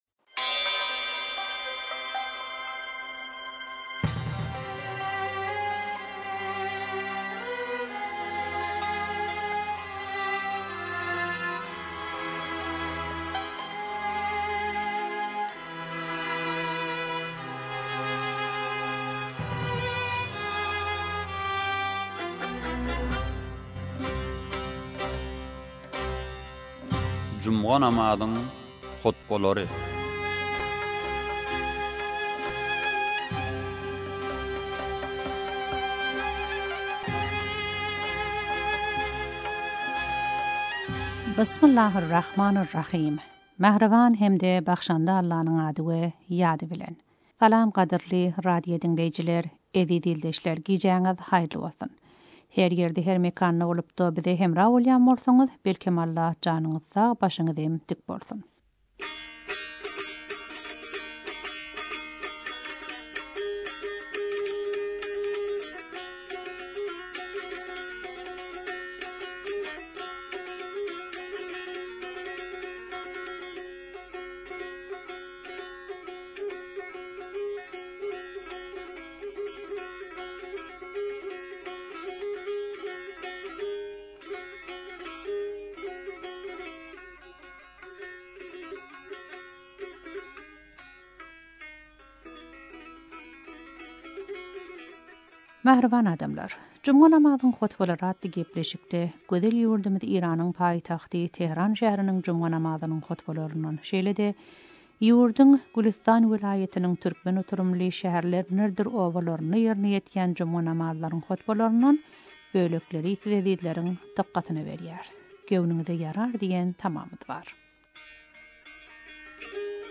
juma namazyň hutbalary